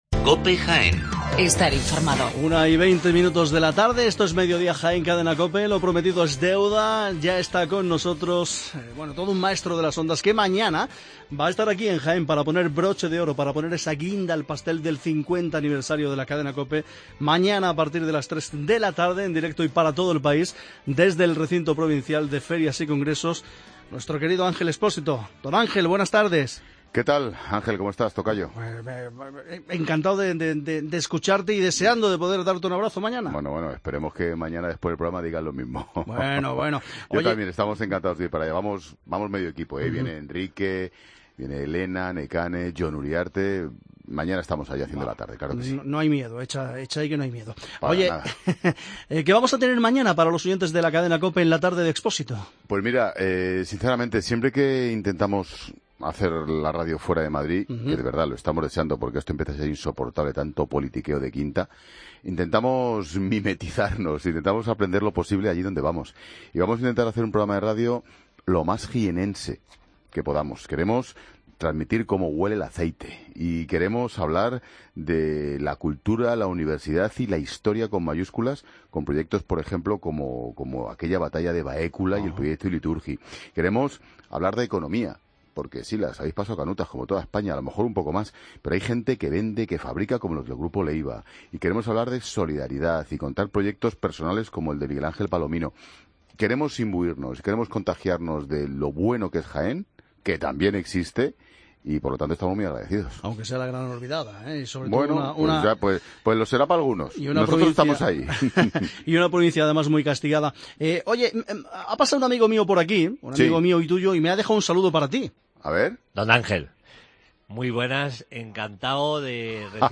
Redacción digital Madrid - Publicado el 14 dic 2016, 13:28 - Actualizado 19 mar 2023, 04:41 1 min lectura Descargar Facebook Twitter Whatsapp Telegram Enviar por email Copiar enlace La Tarde de Expósito se emite desde IFEJA mañana 15 de diciembre